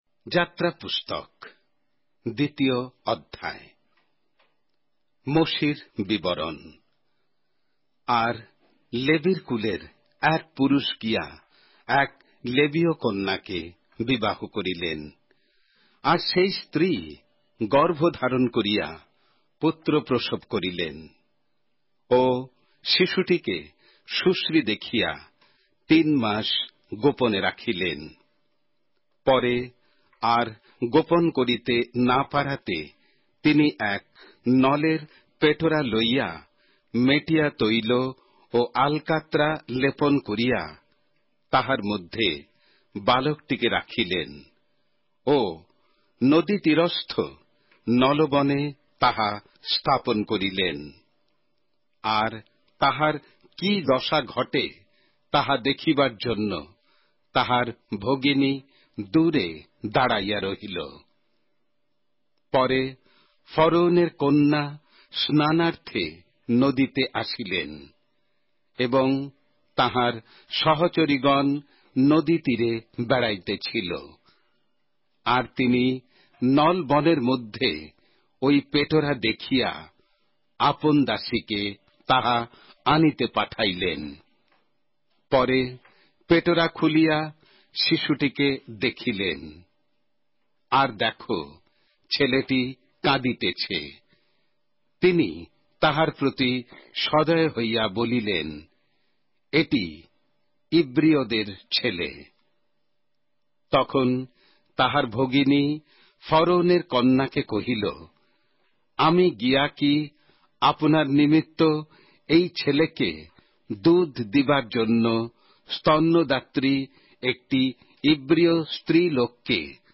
Exodus, chapter 2 of the Holy Bible in Bengali:অডিও আখ্যান সঙ্গে বাংলা পবিত্র বাইবেল অধ্যায়,